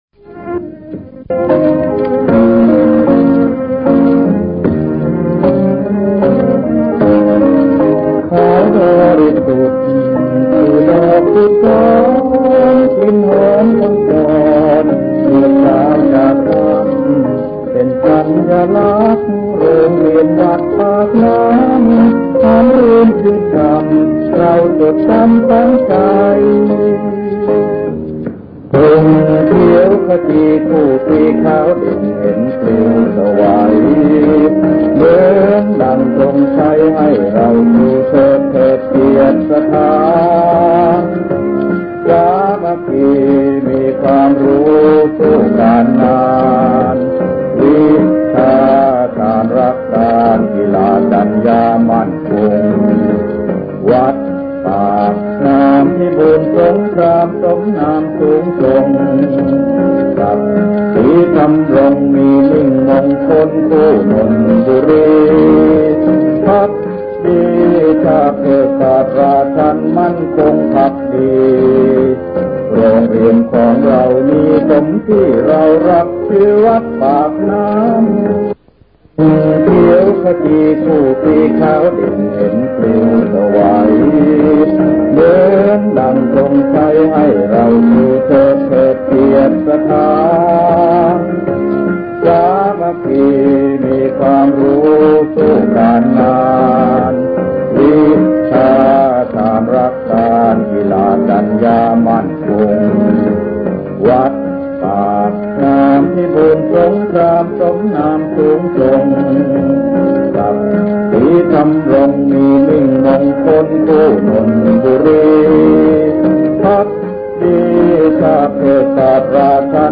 เนื้อเพลงมาร์ชโรงเรียน